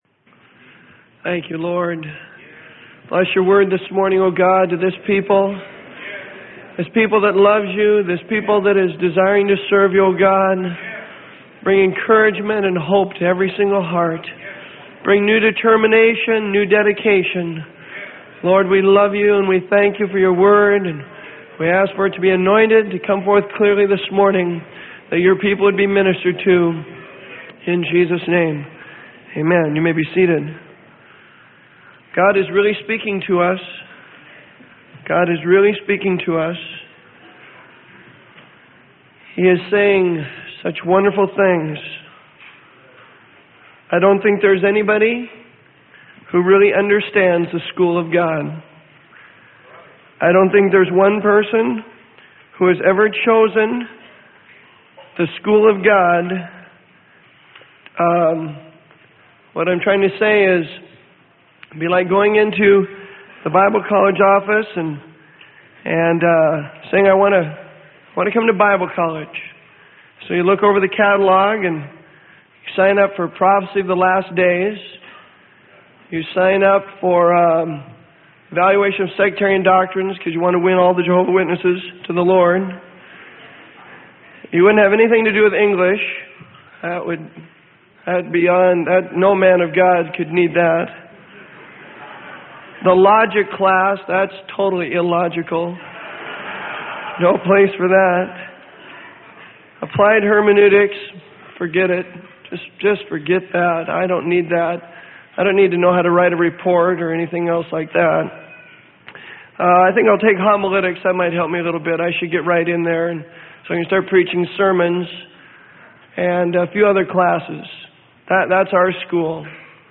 Sermon: It's Only a Matter of Time If.....